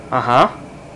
Ah Ha Sound Effect
Download a high-quality ah ha sound effect.
ah-ha-2.mp3